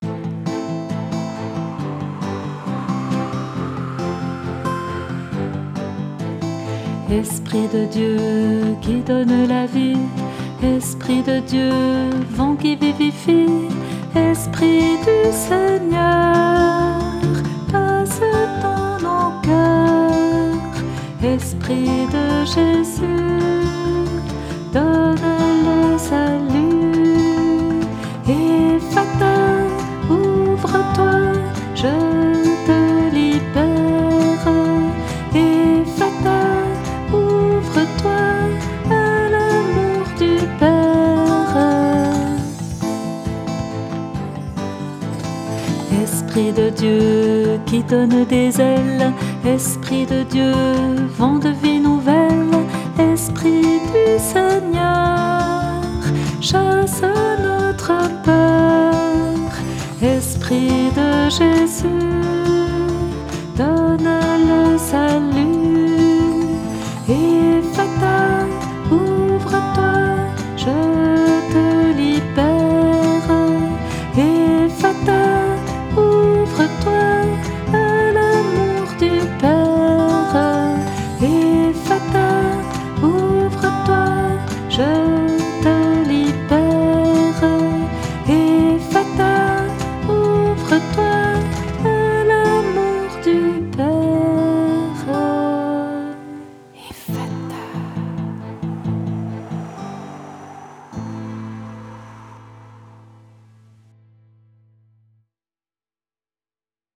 Chant « Ephata !